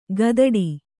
♪ gadaḍi